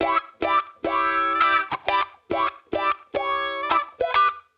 Index of /musicradar/sampled-funk-soul-samples/105bpm/Guitar
SSF_StratGuitarProc2_105E.wav